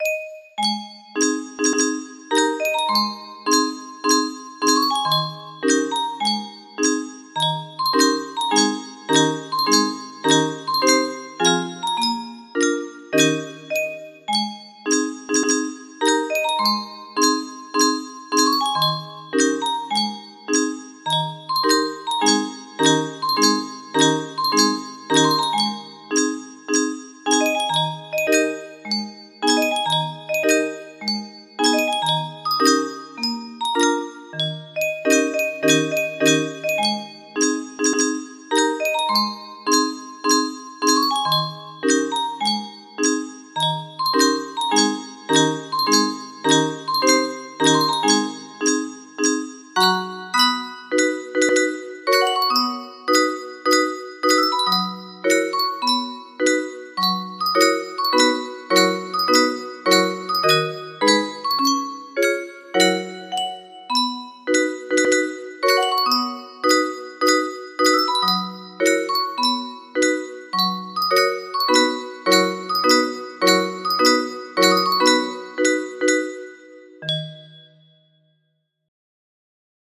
Aida music box melody
Full range 60